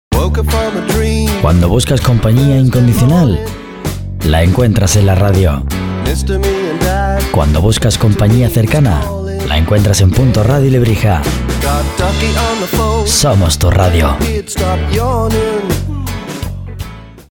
Locutor Profesional con más de 20 años de experiencia.
Sprechprobe: Werbung (Muttersprache):